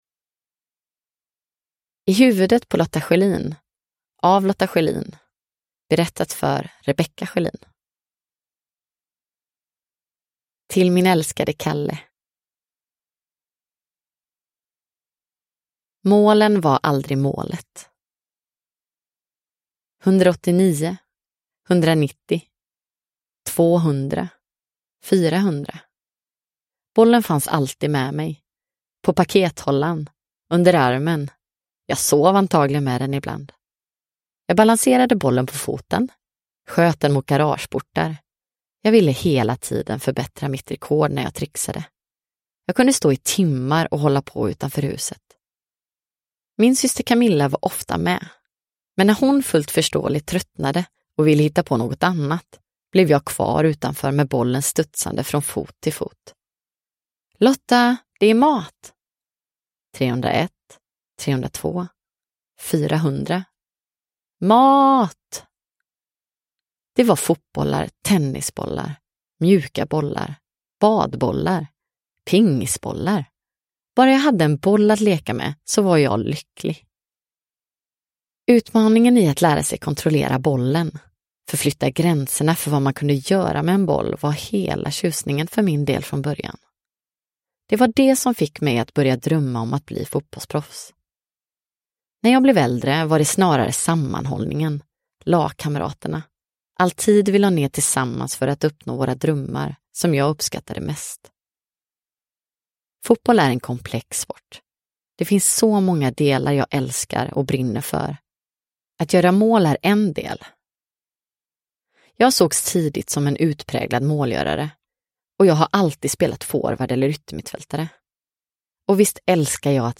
Produkttyp: Digitala böcker
Uppläsare: Lotta Schelin